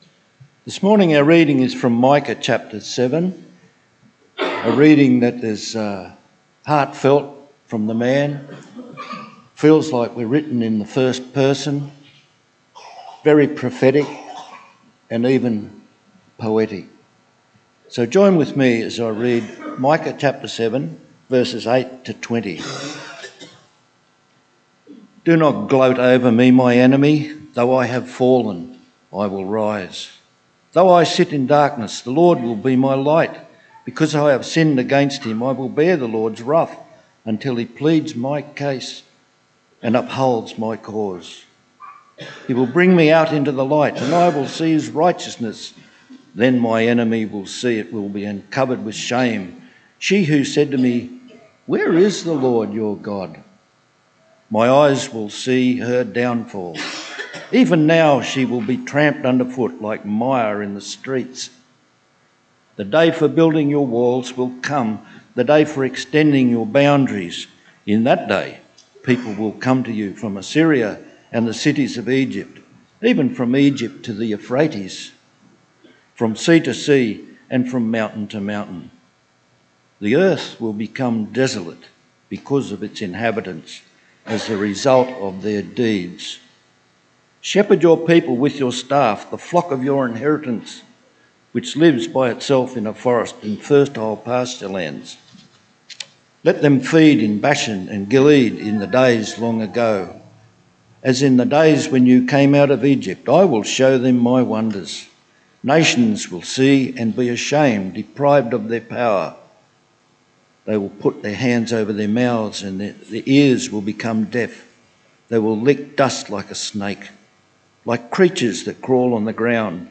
VIDEO & AUDIO SERMONS FOR 2024